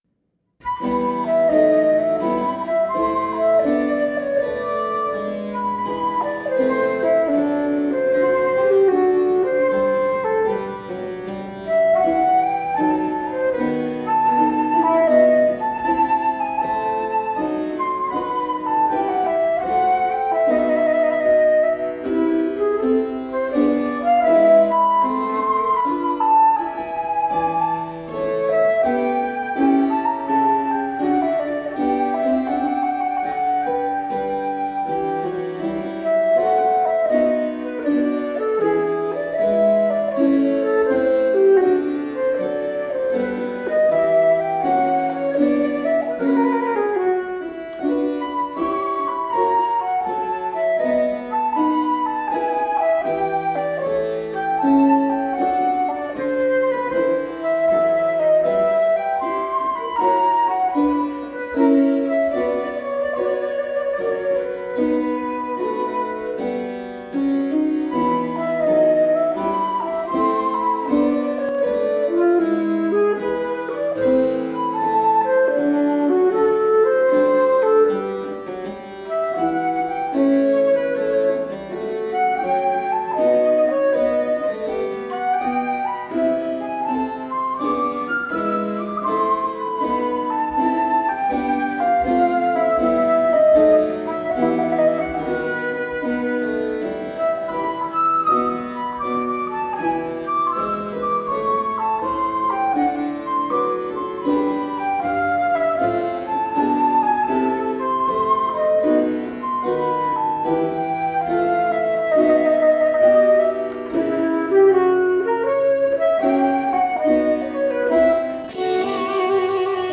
Flute  (View more Intermediate Flute Music)
Classical (View more Classical Flute Music)